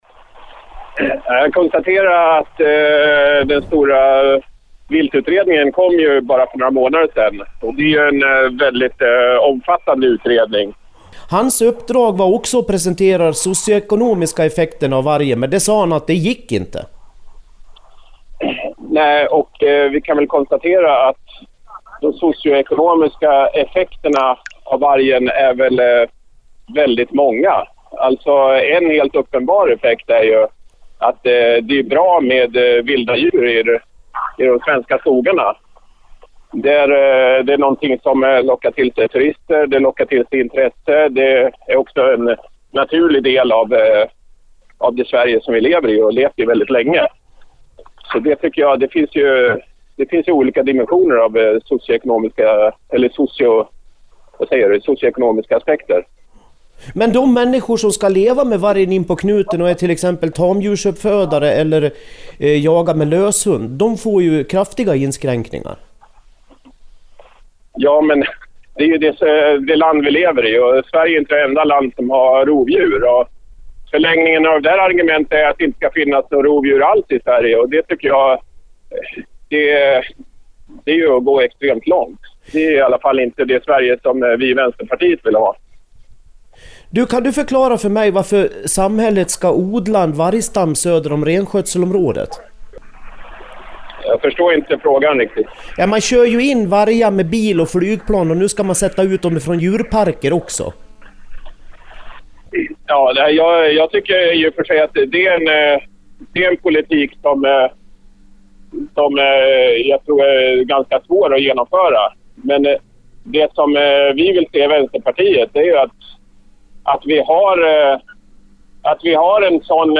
Jakt & Jägare har intervjuat företrädare för vänstern, miljöpartiet och socialdemokraterna.
Men vi hör först vänsterpartisten Jens Holm: Lyssna på radioinslaget här!